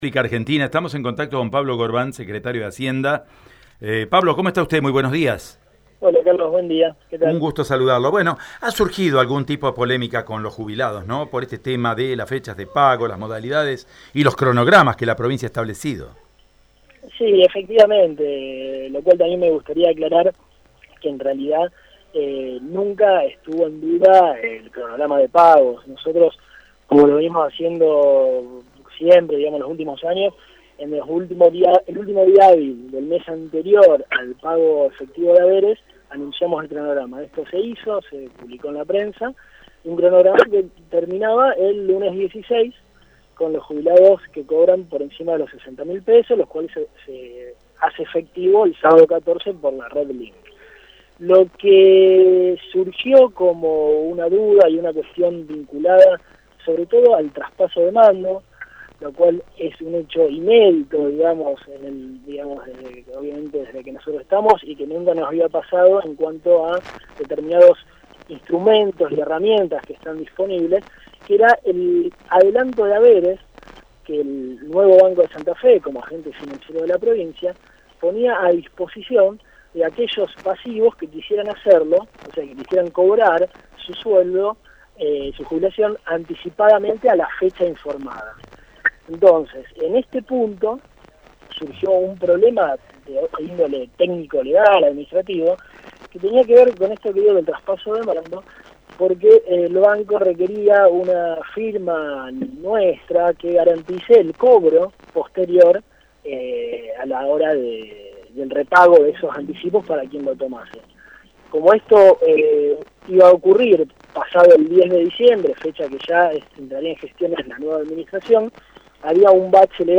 Pablo Gorban, secretario de Hacienda de la provincia, confirmó por Radio EME que solucionaron los problemas que existían para que los jubilados retiren adelantos de sus haberes.